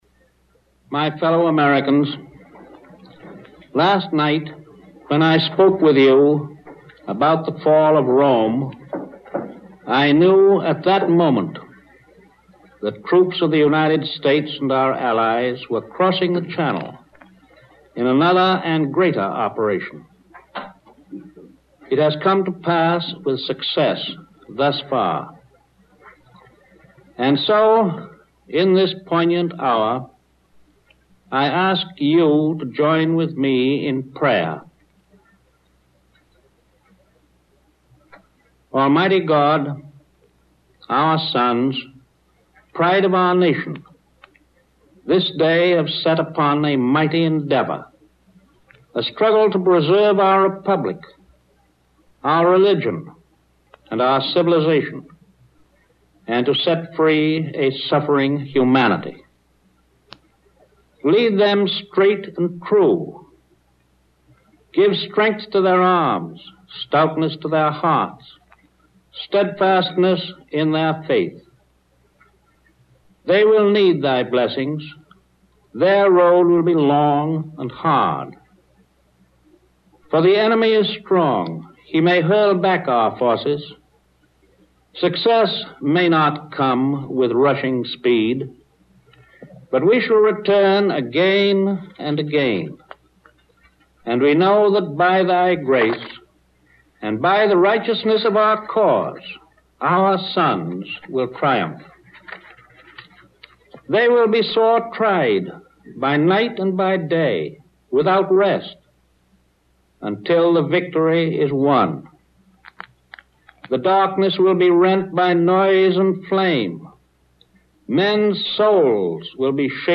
Discours